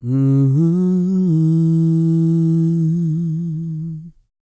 E-CROON P326.wav